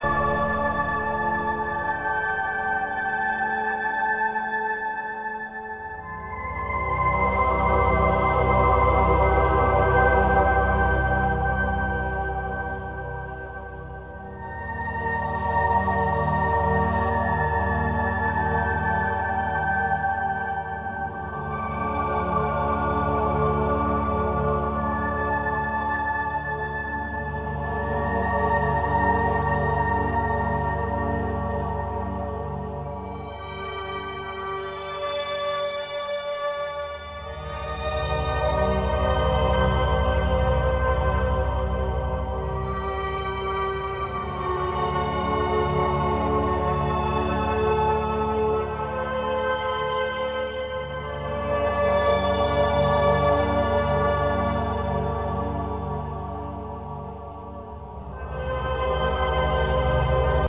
Expansive, meditative, transforming music.